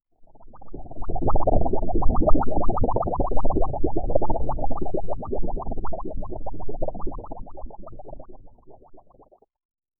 Bubbling Sound Effects - Free AI Generator & Downloads
soft-slow-bubbling-sound--sfsygwxf.wav